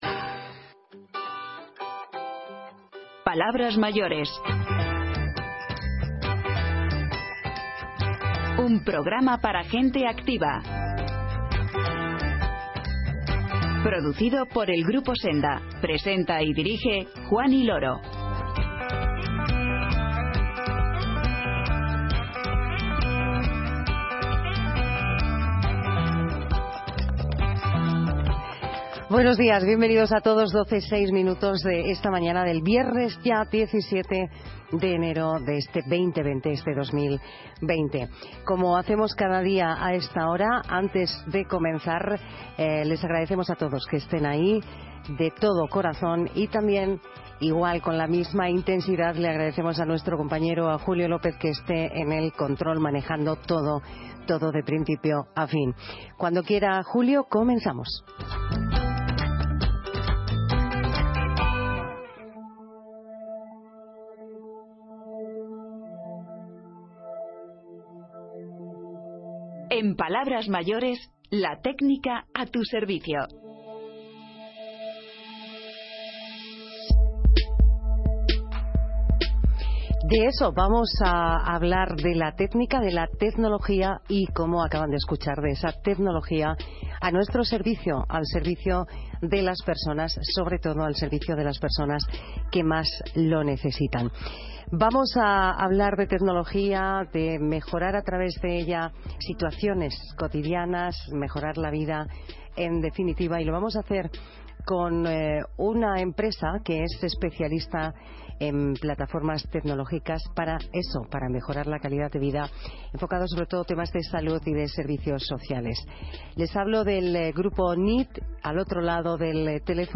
Y en tiempo de recuerdos, escuchamos a algunas de las voces más importantes del año 1956 en nuestro país.